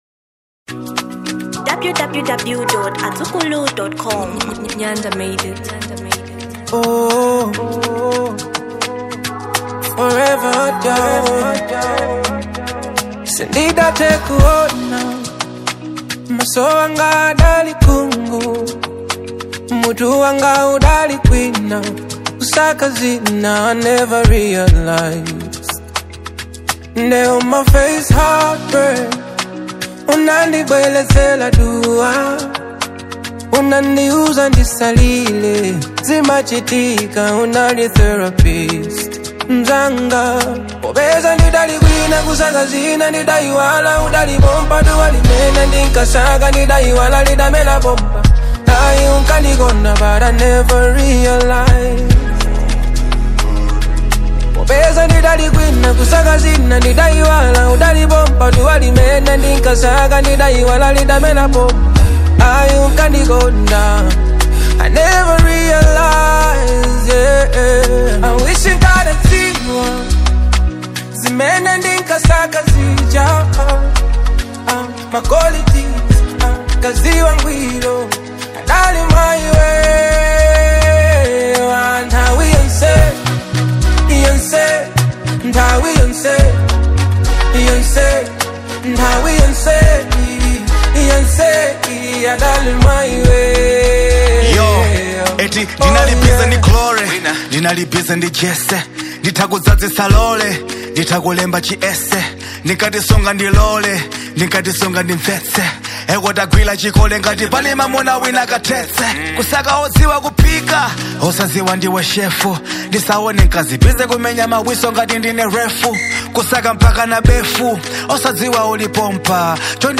Genre Afro Pop